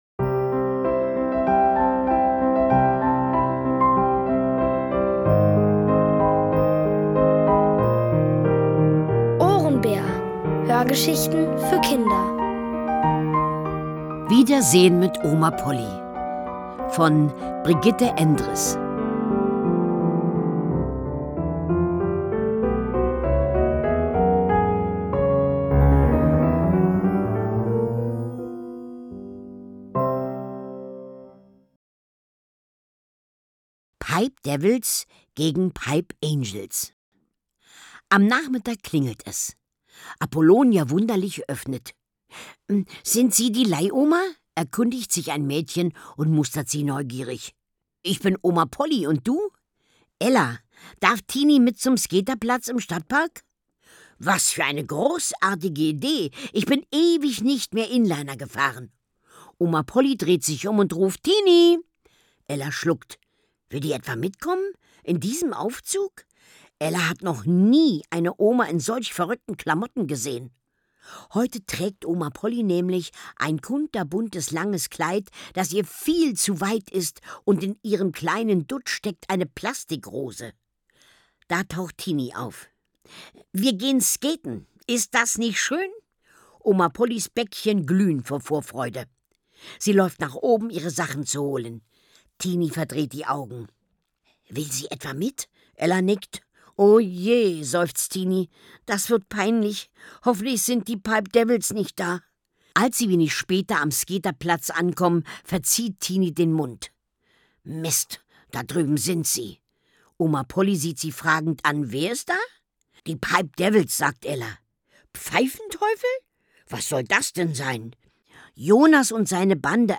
Von Autoren extra für die Reihe geschrieben und von bekannten Schauspielern gelesen.
Es liest: Carmen-Maja Antoni.